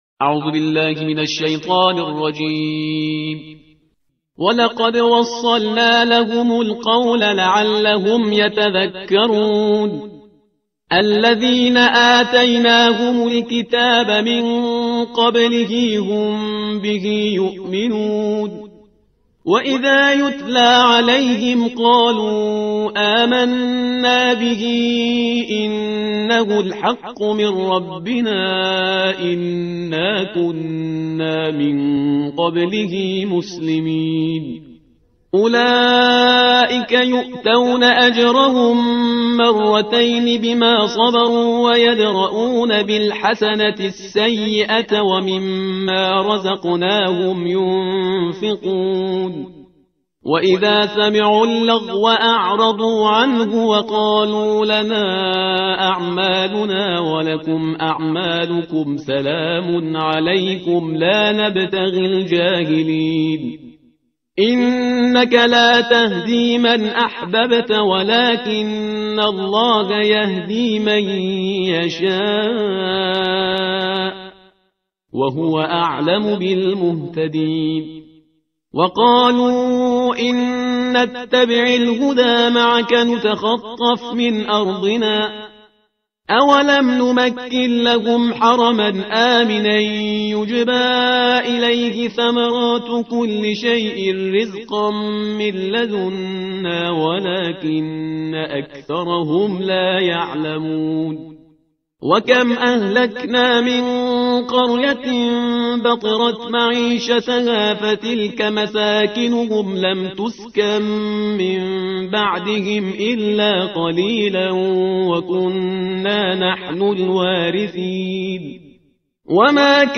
ترتیل صفحه 392 قرآن با صدای شهریار پرهیزگار